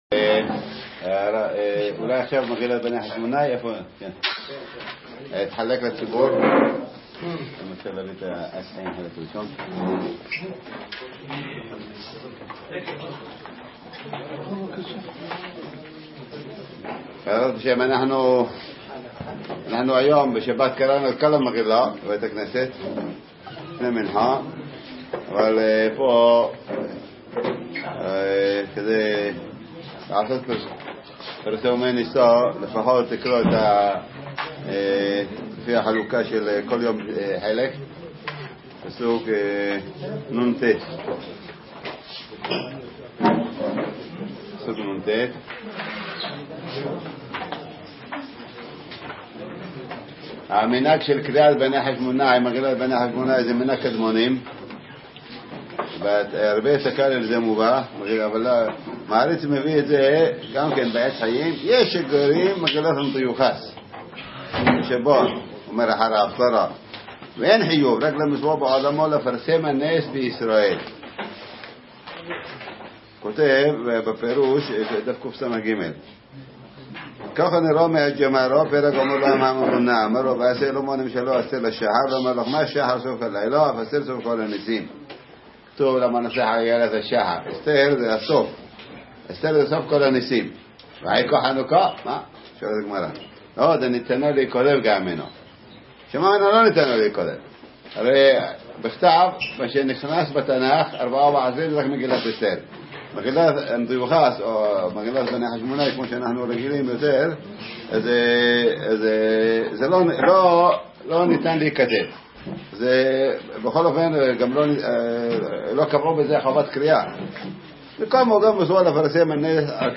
מסיבת חנוכה למשתתפי השיעור השבועי - מוצש"ק מקץ התשע"ט